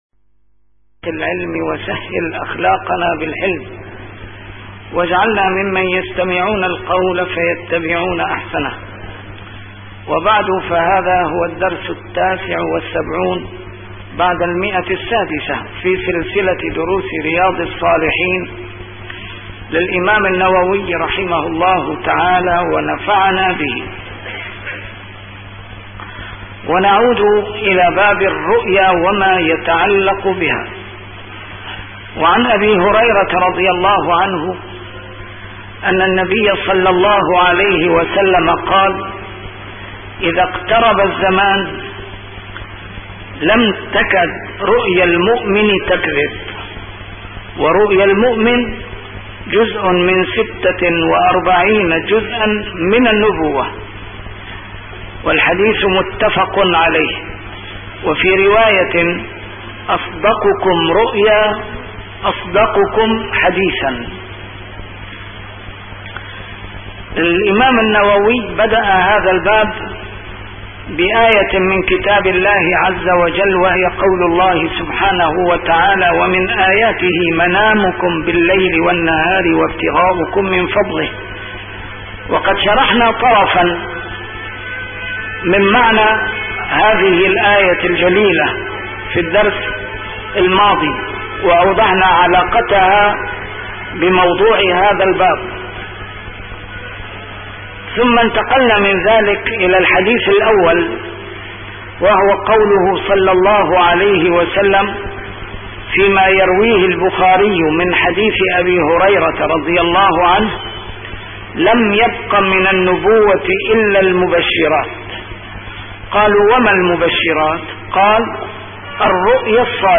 A MARTYR SCHOLAR: IMAM MUHAMMAD SAEED RAMADAN AL-BOUTI - الدروس العلمية - شرح كتاب رياض الصالحين - 679- شرح رياض الصالحين: الرؤيا